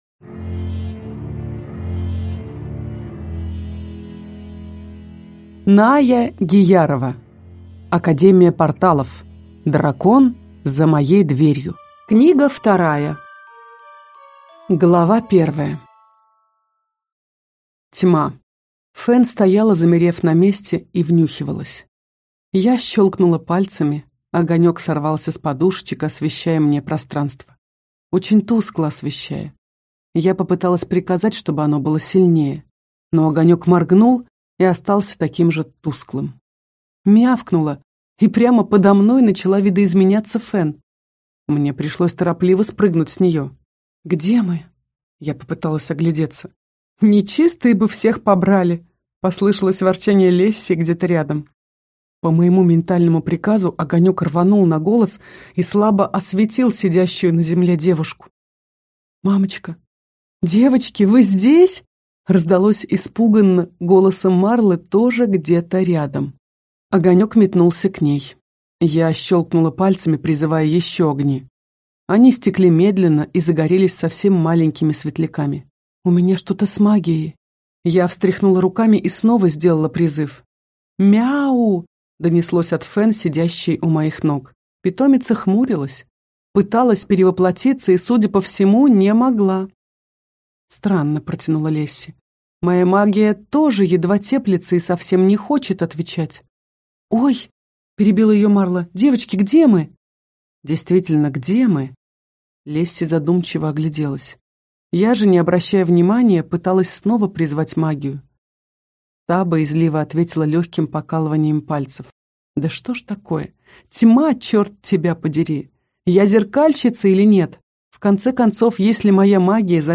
Аудиокнига Академия порталов. Дракон за моей дверью. Книга 2 | Библиотека аудиокниг
Прослушать и бесплатно скачать фрагмент аудиокниги